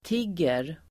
Uttal: [t'ig:er]